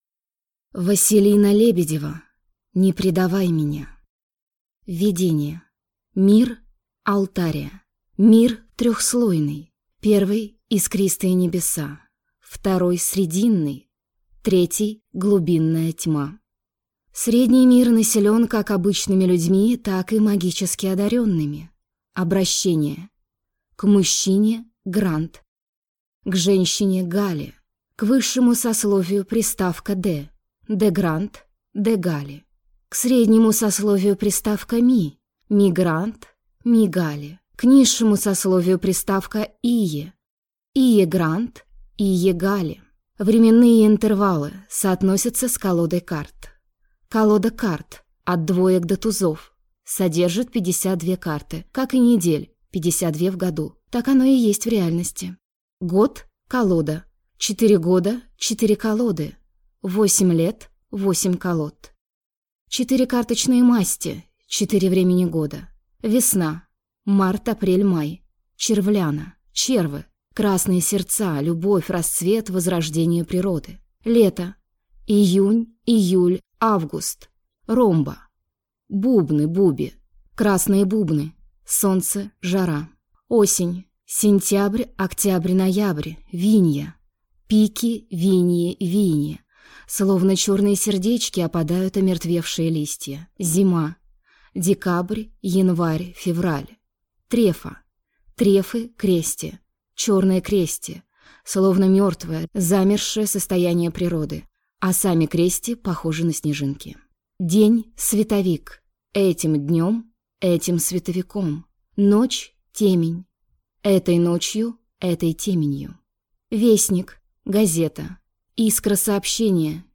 Аудиокнига Не предавай меня | Библиотека аудиокниг
Прослушать и бесплатно скачать фрагмент аудиокниги